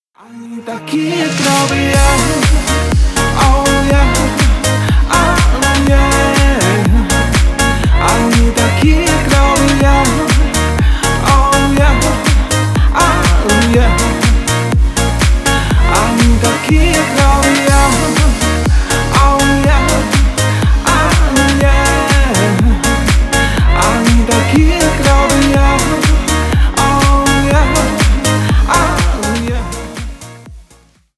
Stereo
Поп